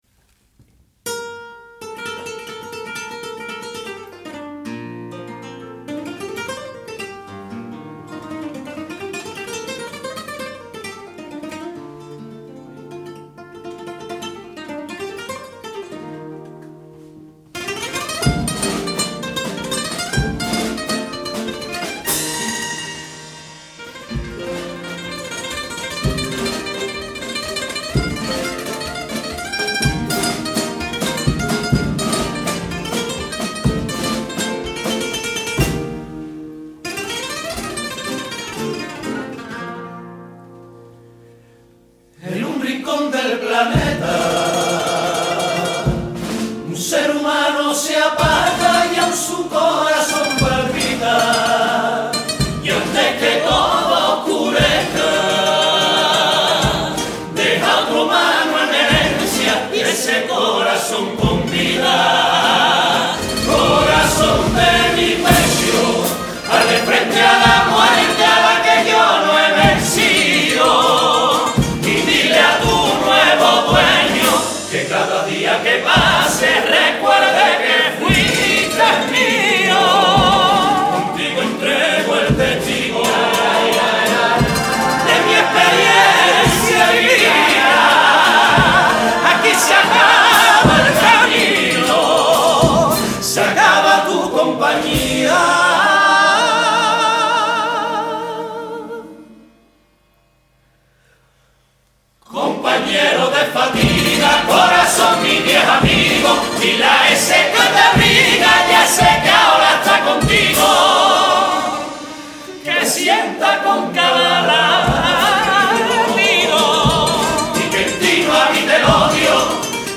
La comparsa "La canción de Cádiz" interpretando el pasadoble ganador en el acto de entrega de los premios Fermin SalvocheaCon el